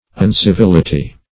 Uncivility \Un`ci*vil"i*ty\, n.